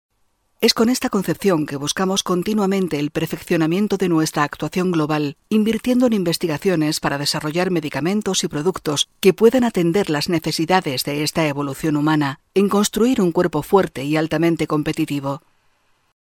locutora